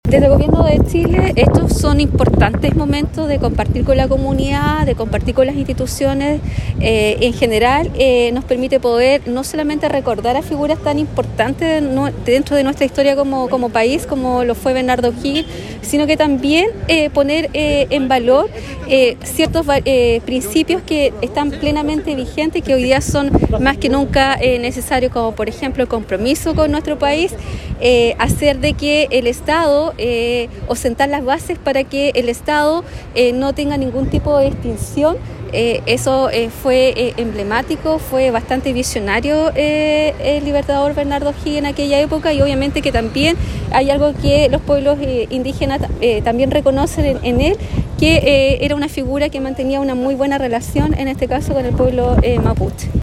Con un acto realizado en Plaza de Armas, que consideró la colocación de ofrendas, Osorno conmemoró el natalicio número 245 del Libertador Bernardo O’Higgins Riquelme, que ocupa un sitial importante en nuestra historia, al participar en la gesta emancipadora para la independencia de Chile.
Por su parte la Delegada Presidencial Provincial, Claudia Pailalef señaló que estas actividades son importantes para compartir con la comunidad, además de dar relevancia a los principios que fundó O’Higgins en las bases del estado actual.